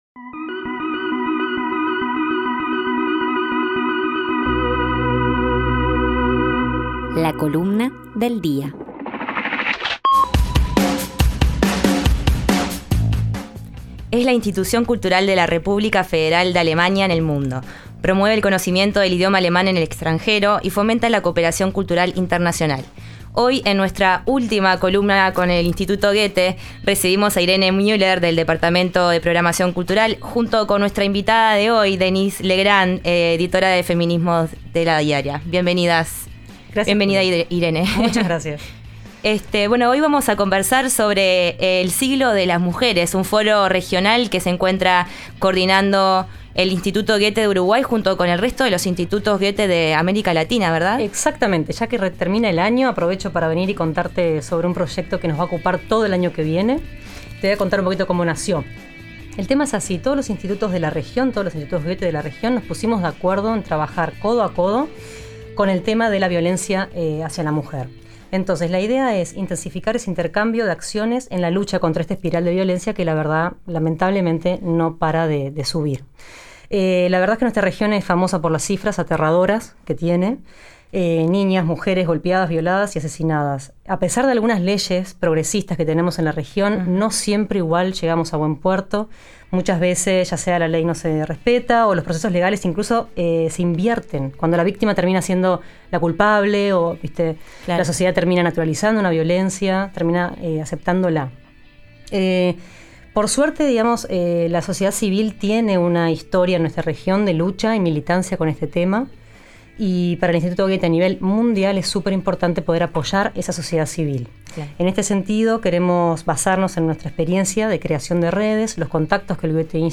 Conversamos con Juana Molina, sobre «ANRMAL», su nuevo disco en vivo, su último disco de estudio «Halo», y sus primeros pasos en la música de la mano de su padre, el reconocido músico y cantante de tango argentino Horacio Molina, entre otras cosas.